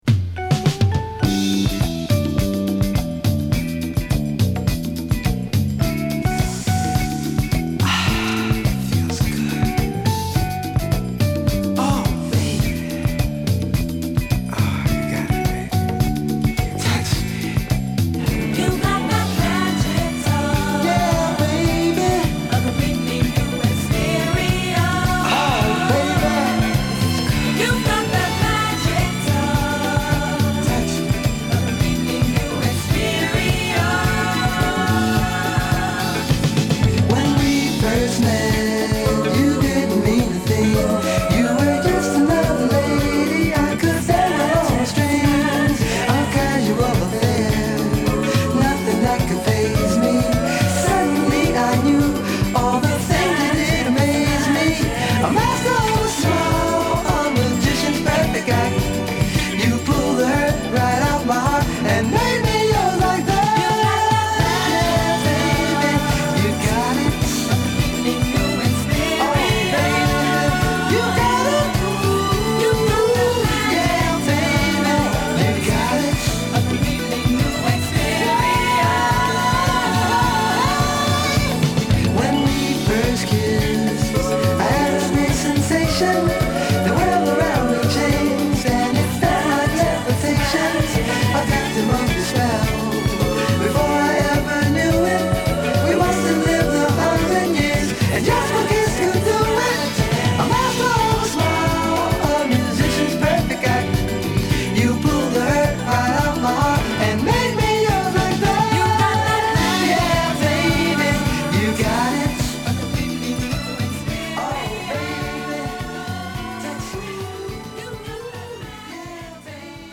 セクシー吐息も交えたモダンソウル／ディスコを披露！
(Stereo)